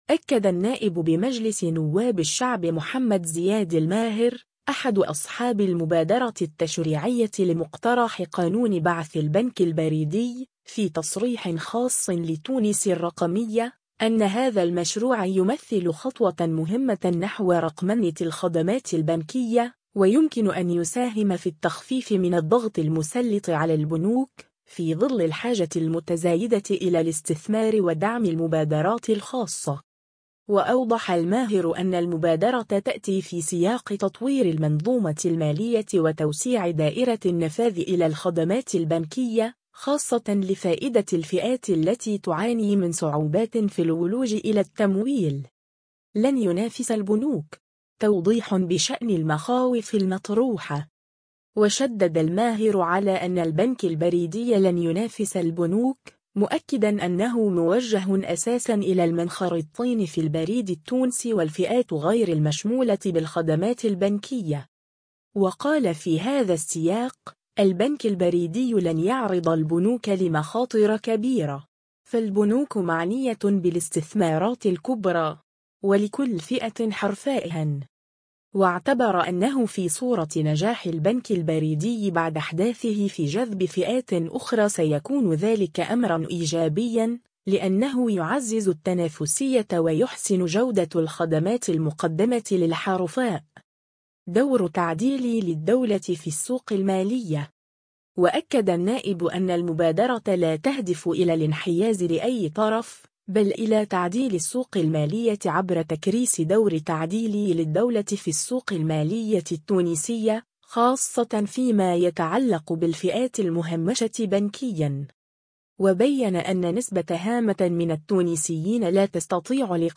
أكد النائب بمجلس نواب الشعب محمد زياد الماهر، أحد أصحاب المبادرة التشريعية لمقترح قانون بعث البنك البريدي، في تصريح خاص لـ”تونس الرقمية”، أن هذا المشروع يمثل خطوة مهمة نحو رقمنة الخدمات البنكية، ويمكن أن يساهم في التخفيف من الضغط المسلط على البنوك، في ظل الحاجة المتزايدة إلى الاستثمار ودعم المبادرات الخاصة.